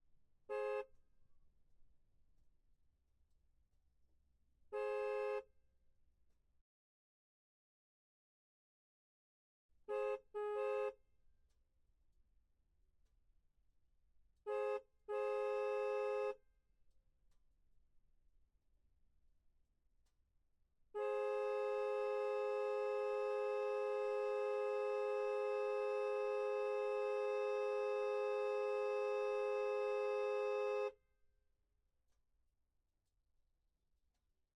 Mercedes_C63_t12_Var_SFX_Horn_Various_Holophone.ogg